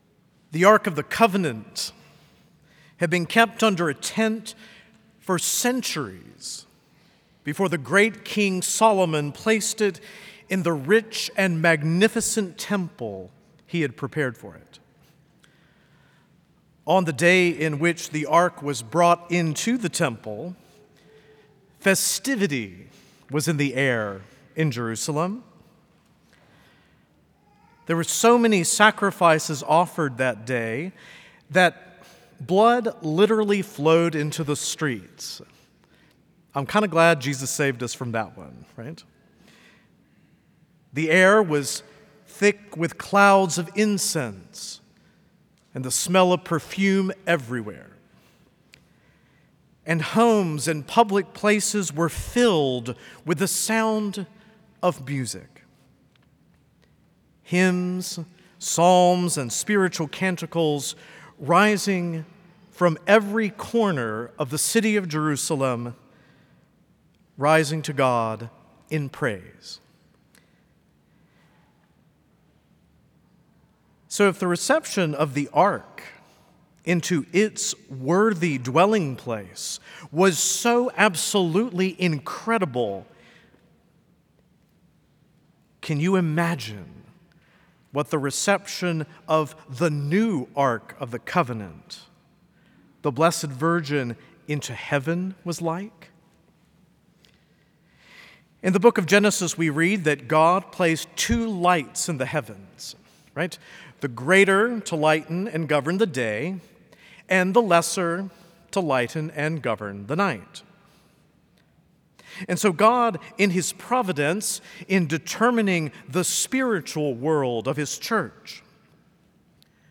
Homily
A homily from the series "Homilies."